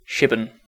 Shipbourne (/ˈʃɪbərn/
SHIB-ərn) is a village and civil parish situated between the towns of Sevenoaks and Tonbridge, in the borough of Tonbridge and Malling in the English county of Kent.
En-Shipbourne.ogg.mp3